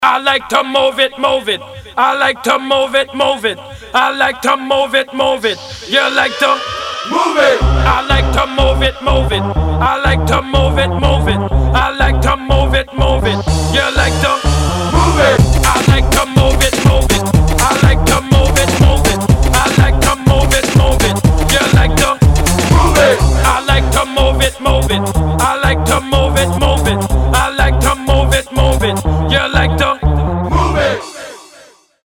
• Качество: 192, Stereo
ритмичные
зажигательные
веселые
dancehall
house
Eurodance
дискотека 90-х
Reggae